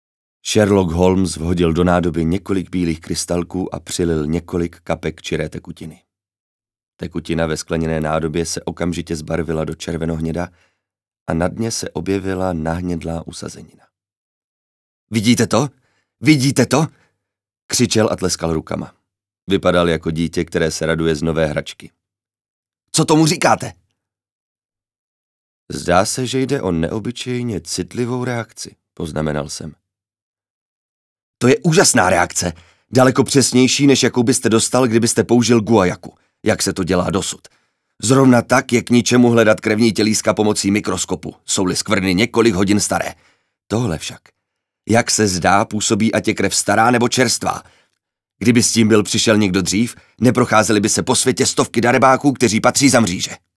ukázka: audio kniha / Sherlock Holmes
audio-kniha-holmes.mp3